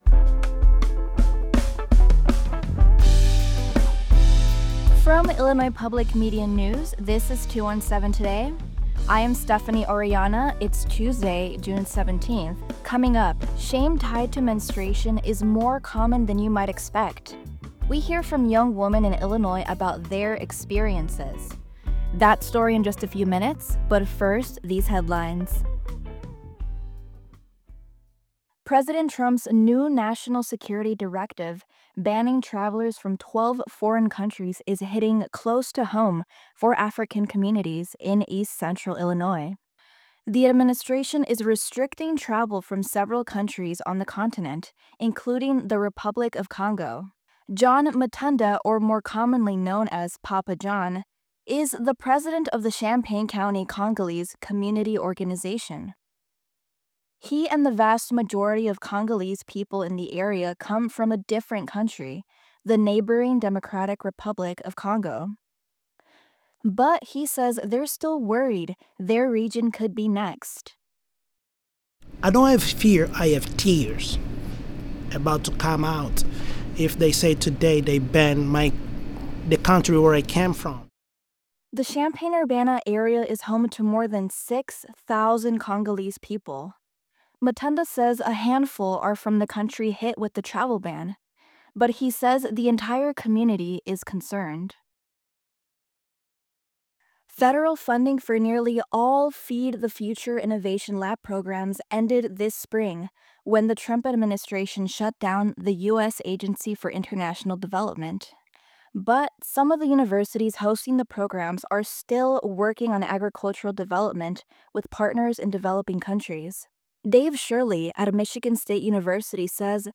In today’s deep dive, shame tied to menstruation is more common than you might expect. We hear from young women in Illinois about their experiences.